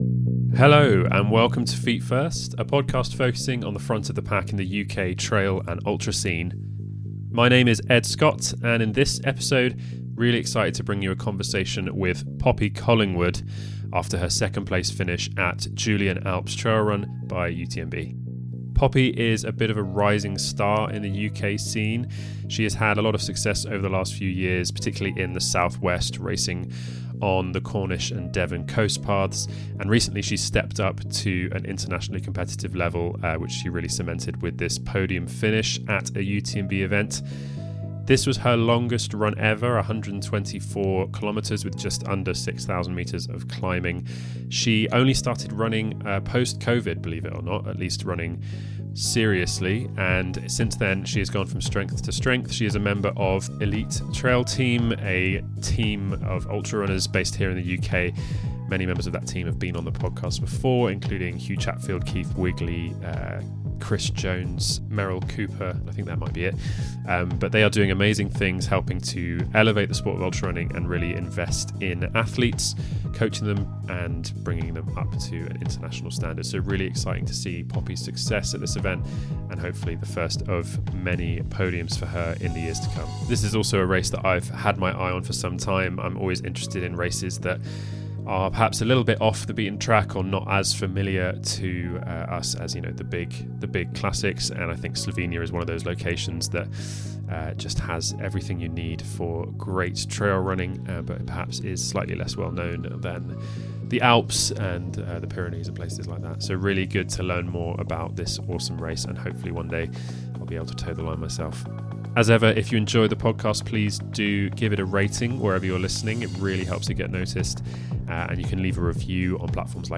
A post-race interview